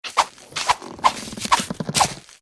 resources/phase_5.5/audio/sfx/burrow.mp3 at 29f25cb59b97a03f634e50d9e57d7703d6855d29
burrow.mp3